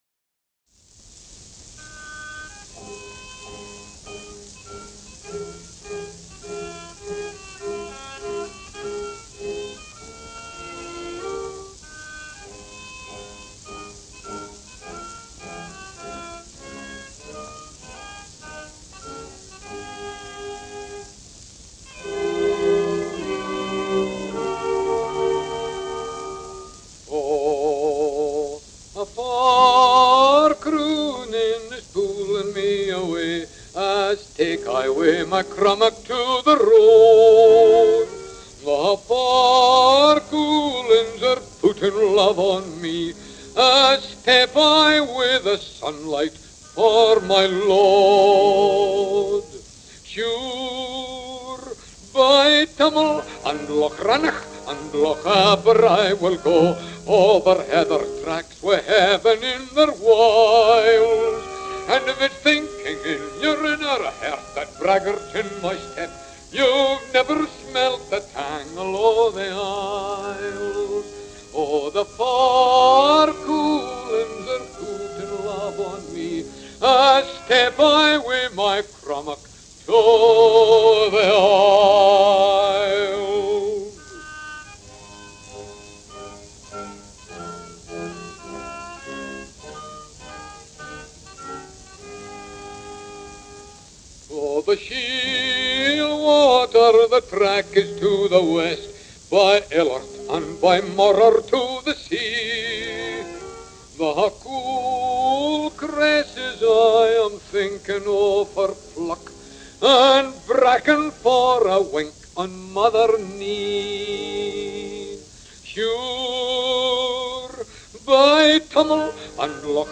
“The Road to the Isles” is one of the most-heard pieces composed for the Highland pipes, which became a certifiable, number-one-with-a-bullet, smash hit after the Scottish raconteur Sir Harry Lauder recorded his arrangement 100 years ago on March 4, 2026.
The 1926 HMV label from the smash hit 1926 recording by Harry Lauder.
Recorded, produced and distributed by His Master’s Voice (HMV), the 78RPM record label listed Lauder’s “Road to the Isles” as “Arr. M. Kennedy Fraser” – arranged by Marjory Kennedy-Fraser.